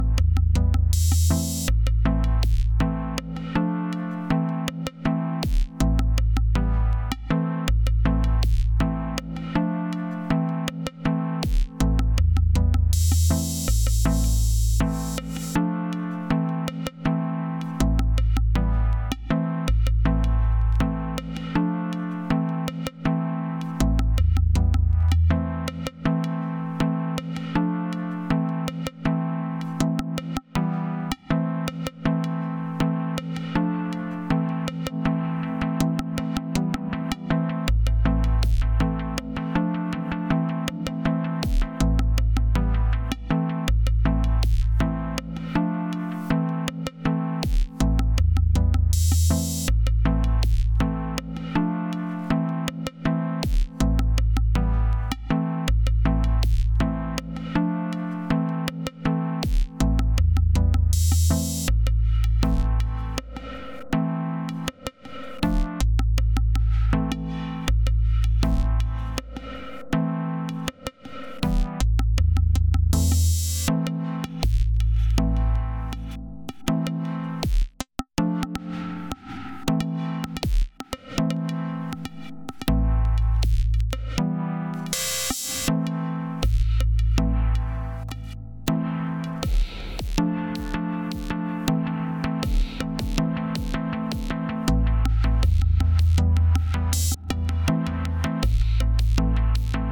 Was going for a dub of all the bugs at once:
• Hung notes (nassty glitchy cymbal)
• Clicks as far as the ear can hear
• Timing going off the rails when changing per-track scale whilst sync’d to DAW
• And the machine full-hung at the end of the recording
Digitone alone:
Reverb near default settings, a little extra on the tail. But I was running Digitone into an expander that was dragging the tails up as well.